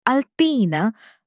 تلفظ صحیح “تین” به این صورت است: “تِين”.
fig-in-arabic.mp3